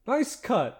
Add voiced sfx